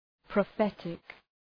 Προφορά
{prə’fetık}
prophetic.mp3